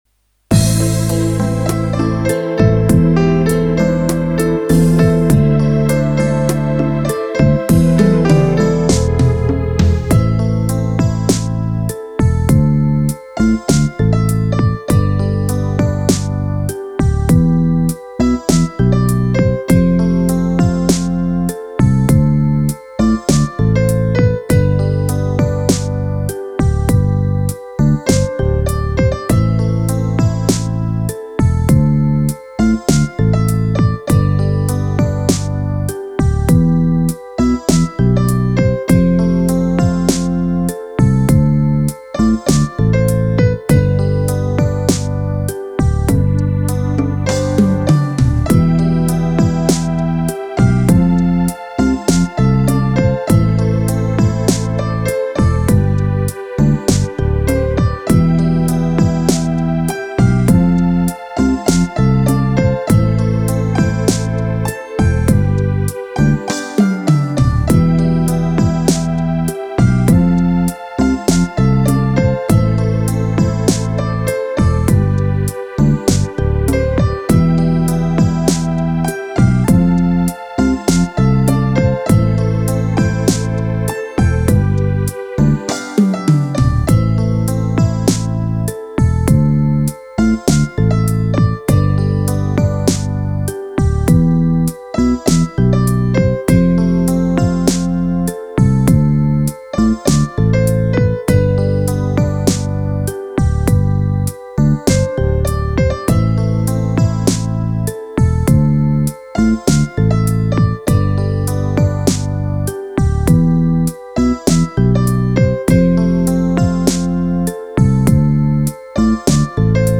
We zijn opnieuw beland in de Ballad & 6/8-categorie van de Roland E-X10, dus het tempo gaat weer een stukje omlaag voor een rustiger, sfeervoller nummer.
Vooral de akkoordenprogressie in de linkerhand is voor mij een sterk punt in deze demo.
Het resultaat klinkt aangenaam, evenwichtig en muzikaal overtuigend.
Tempo: 050 BPM
Roland E X 10 Ballad 043 16 Beat Ballad Mp 3